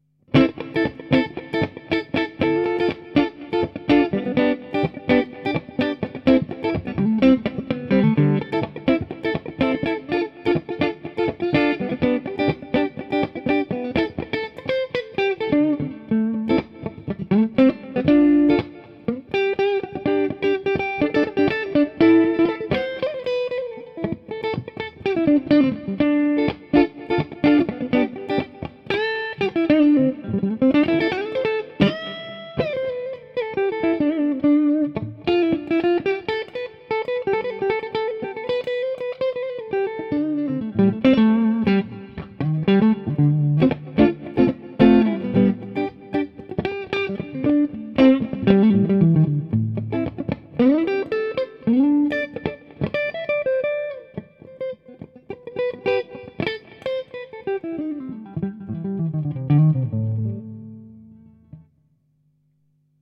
Je prends un peu plus le temps d’explorer les sons clean durant ce deuxième jour, et ce qui me plait alors c’est le côté « rentre dedans », il faut agresser la guitare, mettre son énergie dedans, mais pourtant on peut y aller finement sans soucis.
Bien sur on a pas un son moderne, mais du vrai son vintage a block.
truevintage_clair_rythm.mp3